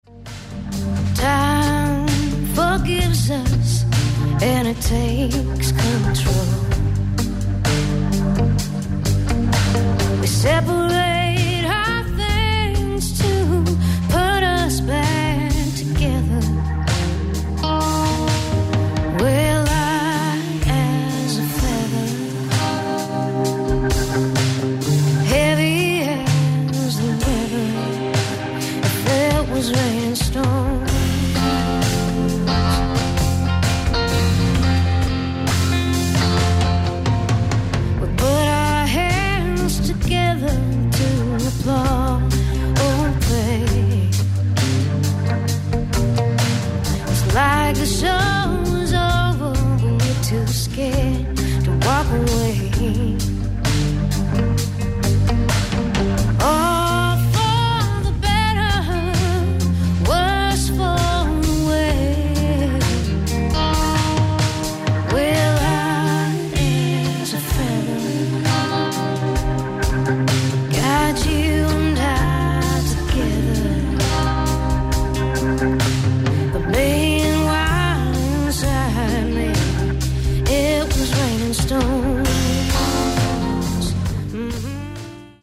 ライブ・アット・スタジオ 104,パリ、フランス 12/07/2009
（アナウンスがカブる楽曲もあります）